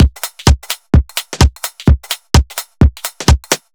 Index of /musicradar/uk-garage-samples/128bpm Lines n Loops/Beats